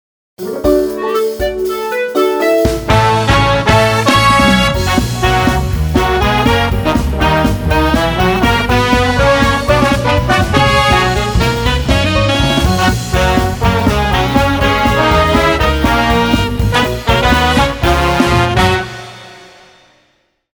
明るく爽やかなビッグバンドジャズで表現しました。
Trombone
BIG BAND / JAZZ